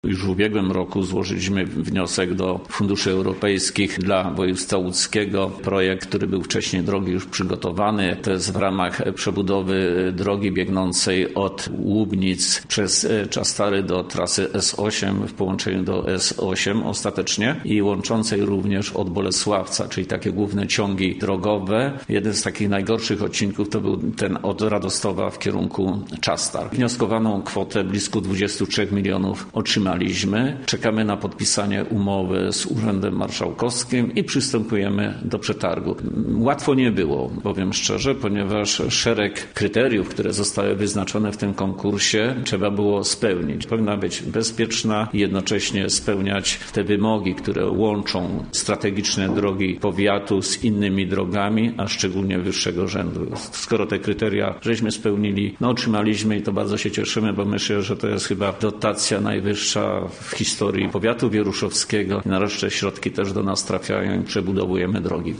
– mówił starosta powiatu wieruszowskiego, Stefan Pietras.